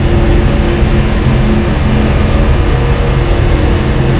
bigmachine_loop_02.wav